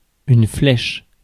Ääntäminen
IPA : /dɑː(ɹ)t/